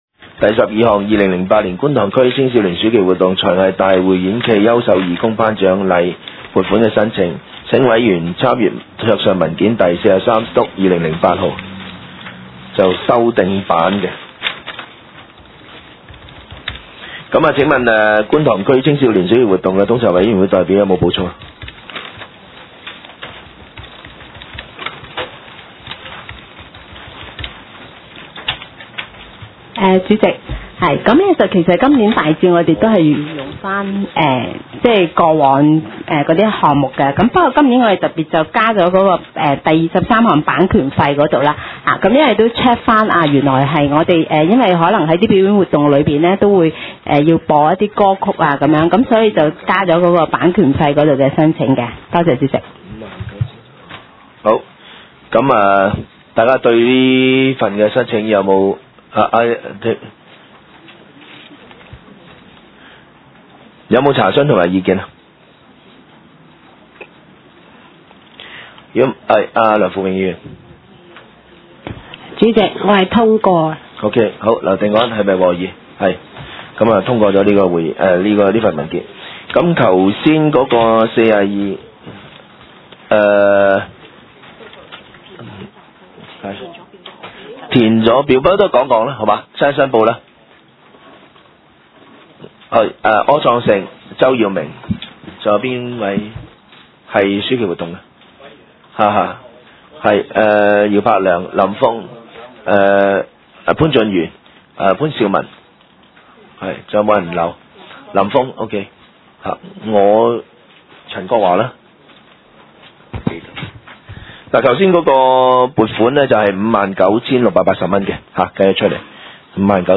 第五次會議記錄
九龍觀塘同仁街6號觀塘政府合署3樓觀塘民政事務處會議室